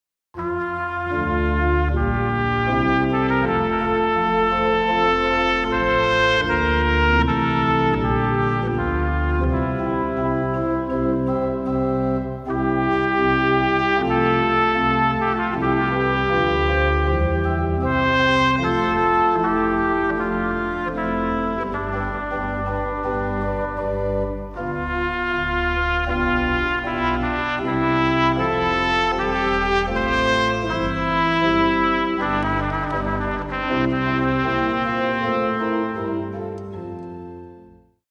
Ceremonial Music Options